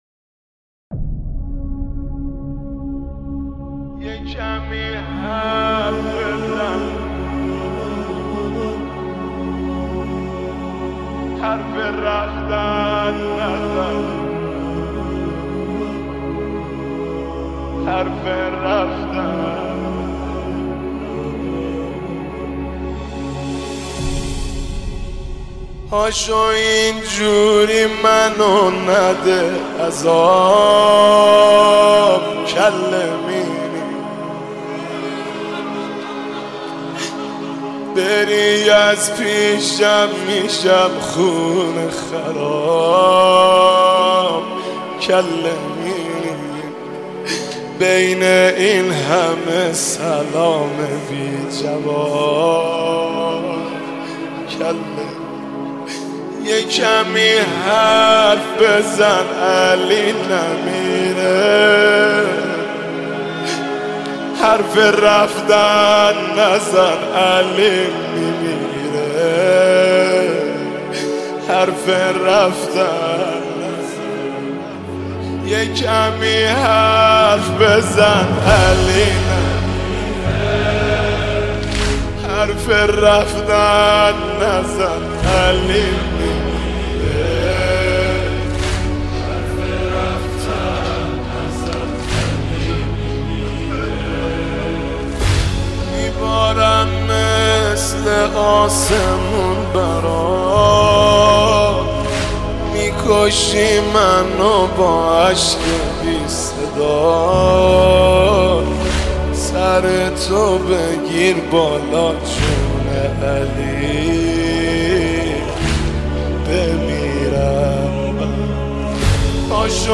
عزاداری و مدیحه‌سرایی در رثای مقام شامخ بانوی دو عالم، حضرت فاطمه زهرا (س) در ادامه قابل بهره برداری است.
مداحی